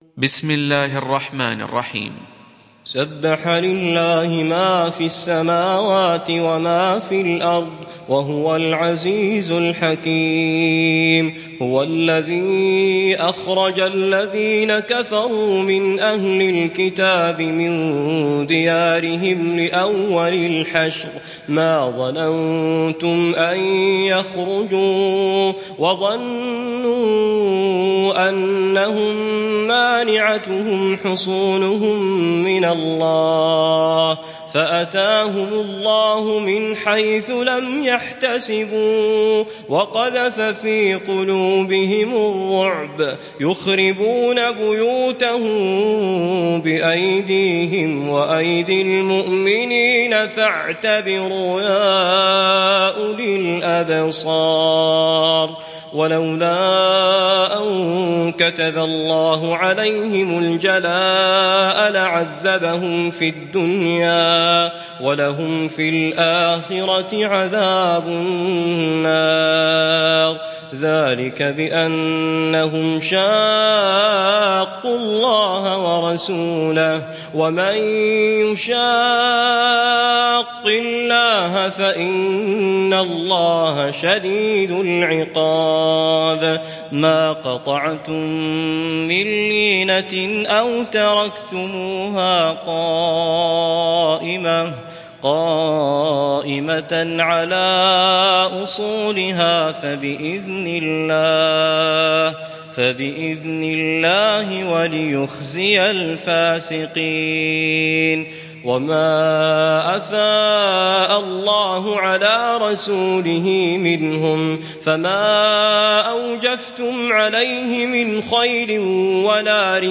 تحميل سورة الحشر mp3 بصوت توفيق الصايغ برواية حفص عن عاصم, تحميل استماع القرآن الكريم على الجوال mp3 كاملا بروابط مباشرة وسريعة